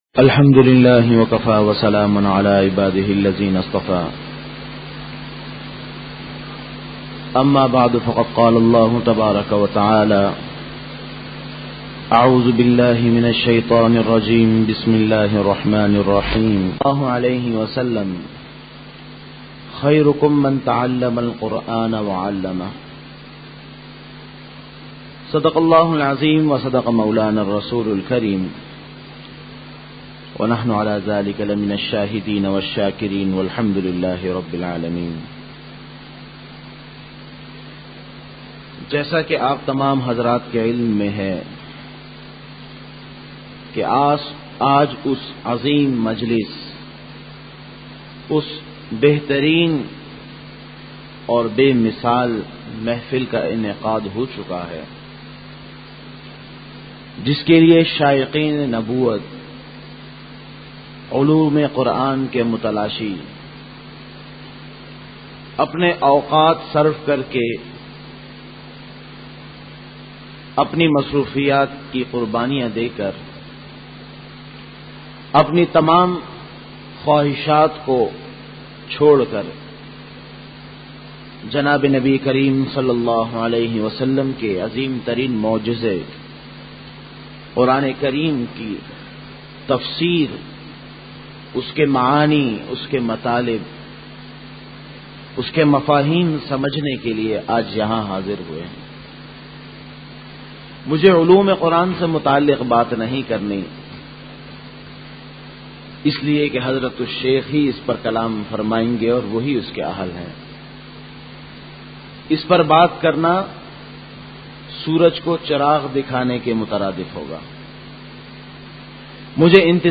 افتتاحی بیان